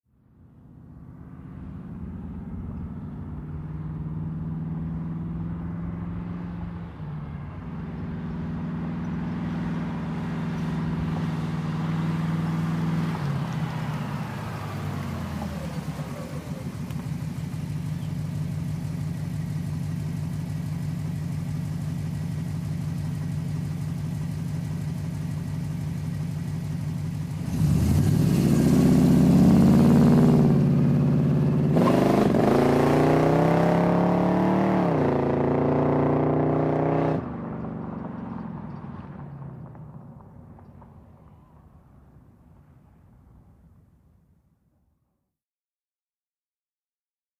1958 Chevrolet Impala, In Fast, Stop Medium Cu, Idle, Away with Acceleration.